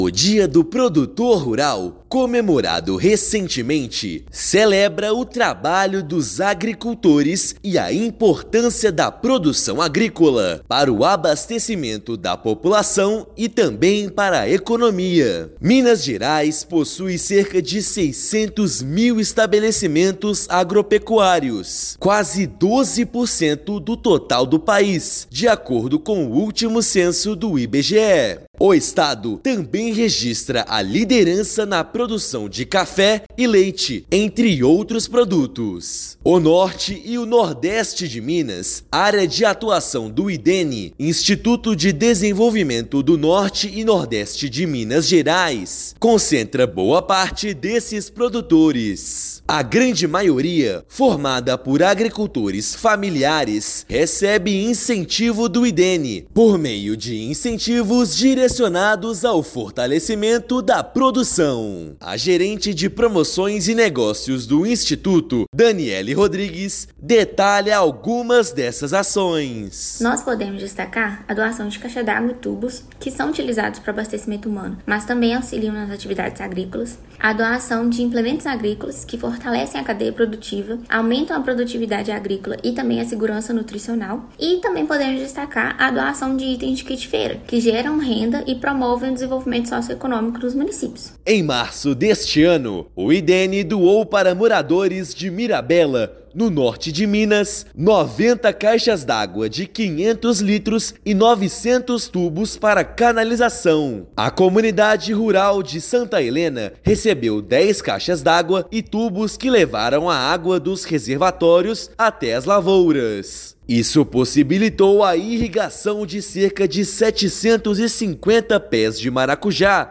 [RÁDIO] Agricultores relatam benefícios com ações de fomento do Idene no Norte e Nordeste de Minas Gerais
Neste 28/7, Dia do Produtor Rural, conheça a história de três produtores beneficiados com as iniciativas do Instituto. Ouça matéria de rádio.